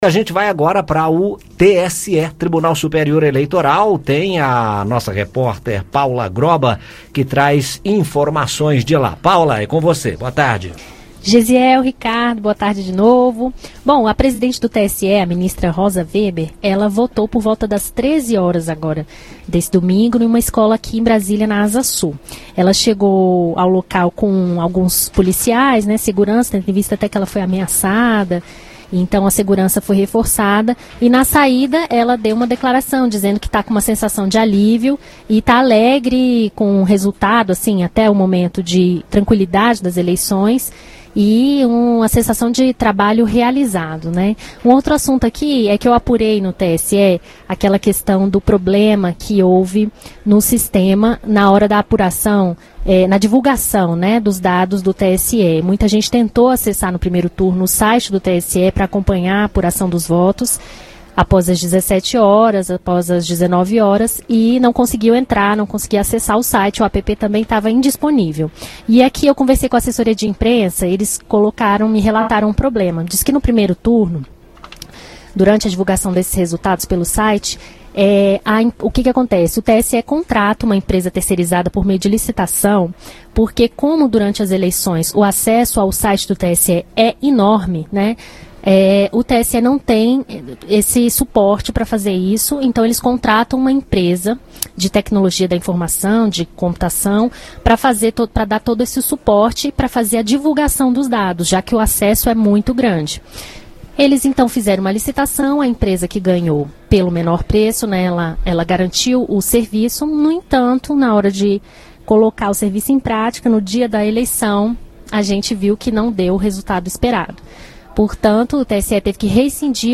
Rosa Weber fala de trabalho realizado ao votar em Brasília